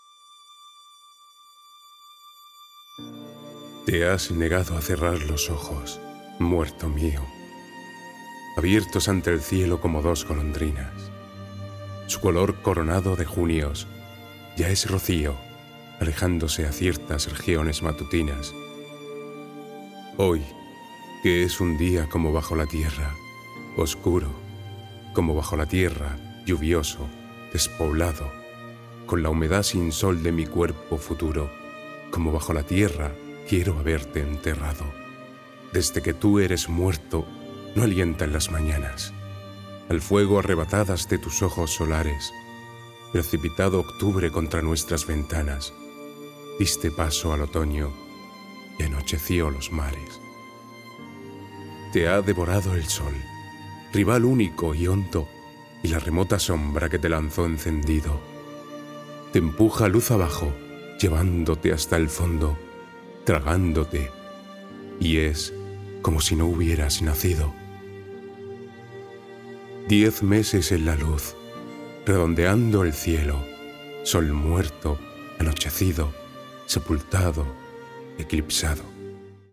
Voice actor for television, radio, film commercial recordings. Dramatic interpretation. Company videos, etc
Sprechprobe: Sonstiges (Muttersprache):
Voice actor who can be kind, energetic, arrogant, corporate, compassionate, rebellious, caring, evil, gentle, persuasive ......